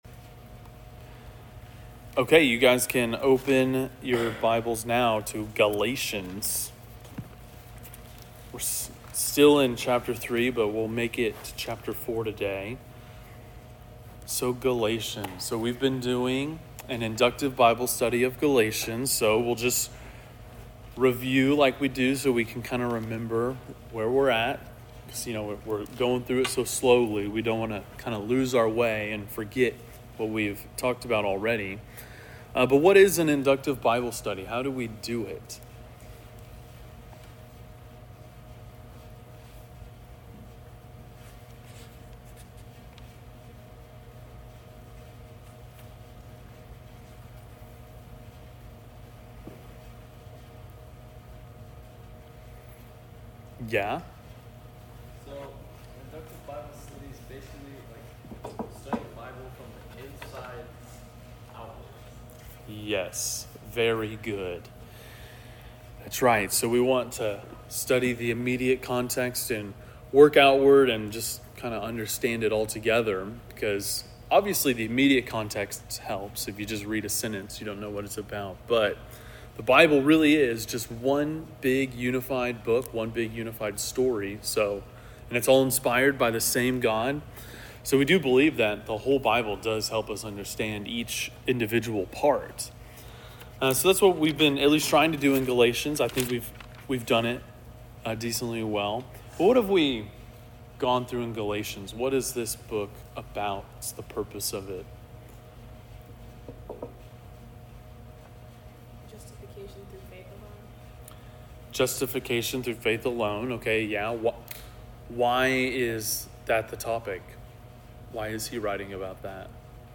Galatians 3:26-4:5 (Inductive Bible Study)